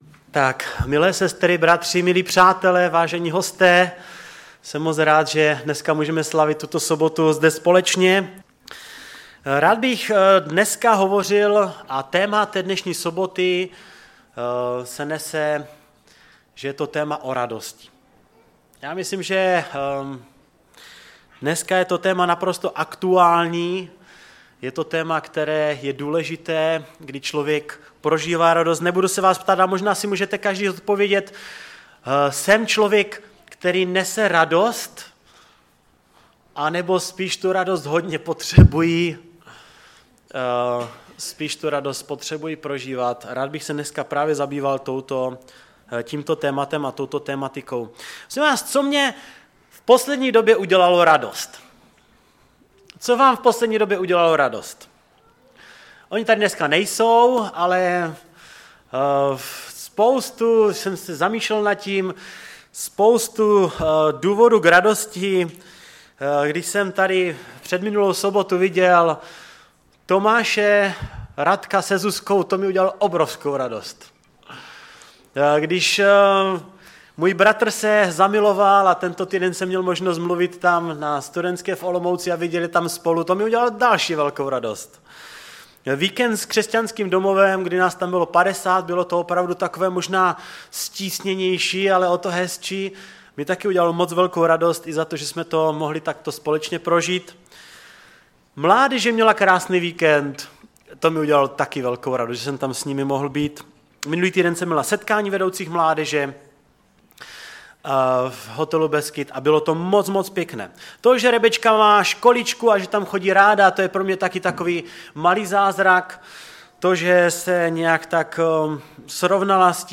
Kázání
ve sboře Ostrava-Radvanice v rámci bohoslužby pro přátele